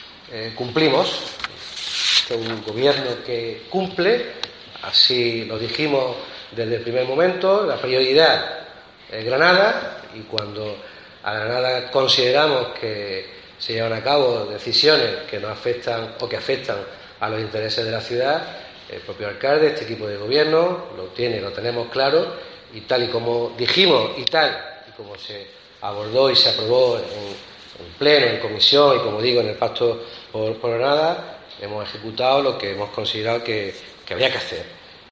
Jacobo Calvo, portavoz del equipo de gobierno